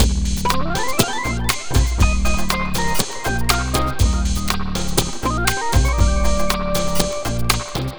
Astro 5 Full-C.wav